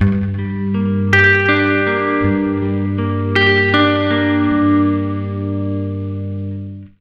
80MINARP G-R.wav